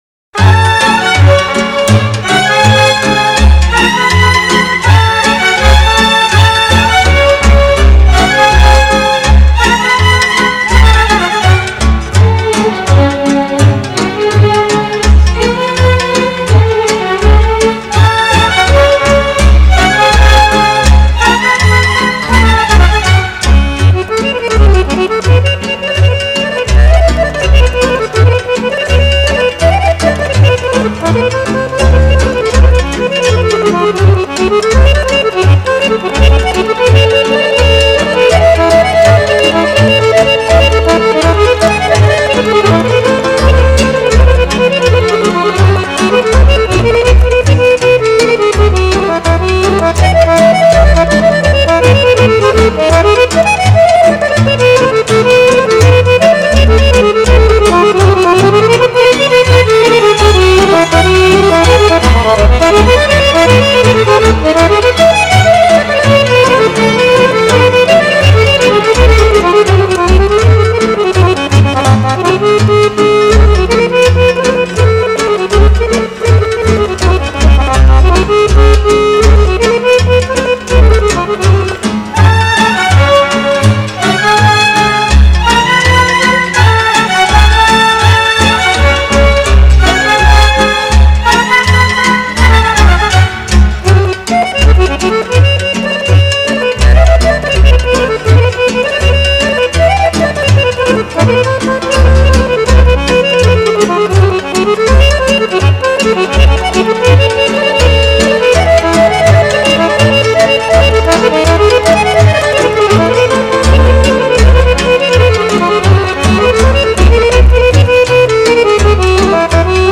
Играет молдавский аккордеонист
Народные песни и танцы